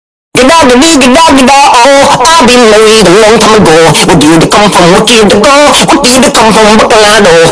Gegagedigedagedago EARRAPE Meme Effect sound effects free download